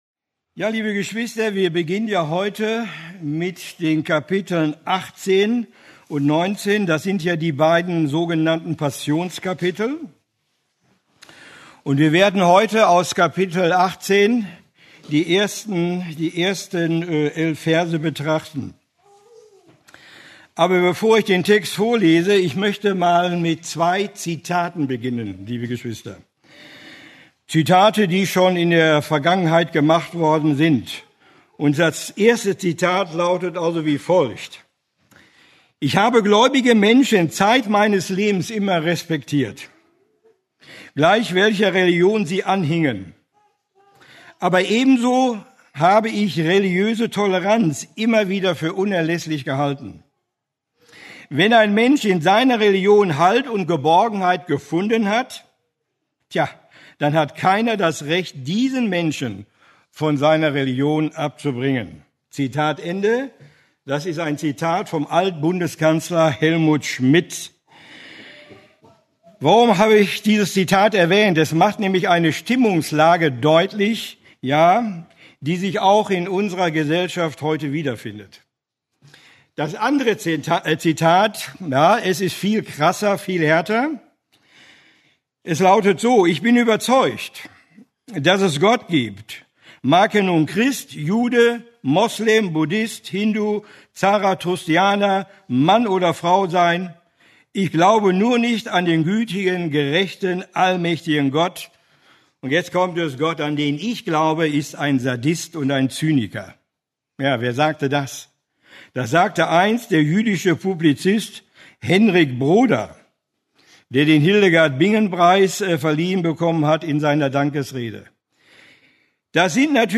Eine predigt aus der serie "Johannes Evangelium."